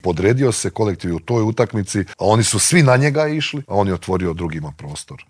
Nakon svjetskog srebra svi se nadaju ponovnom uzletu na smotri najboljih rukometaša starog kontinenta, a o očekivanjima, željama, formi te o tome zašto se u sportu nema strpljenja s trenerima i izbornicima te kako su se nekada osvajale svjetske i olimpijske i klupske titule u Intervjuu Media servisa razgovarali smo bivšim reprezentativcem, legendarnim Božidarem Jovićem.